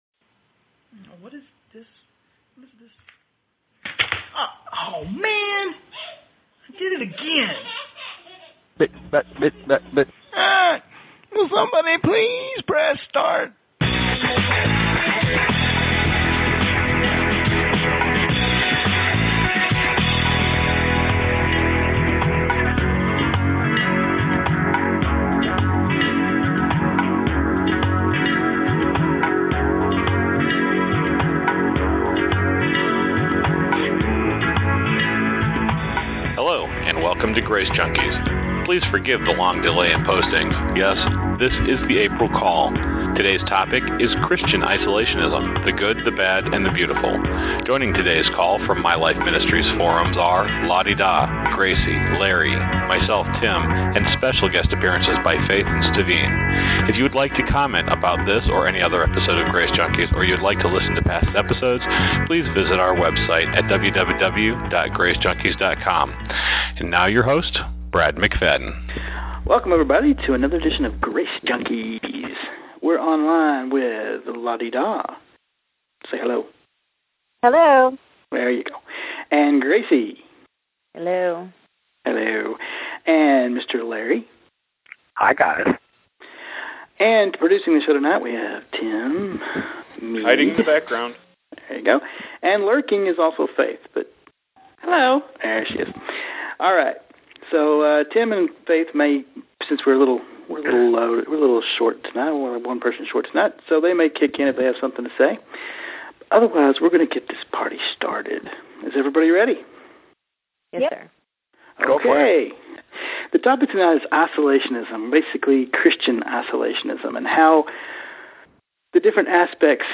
– This call was recorded in April. The topic is Christian Isolationism. How it can hurt us, and when its good for us.